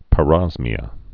(pə-rŏzmē-ə)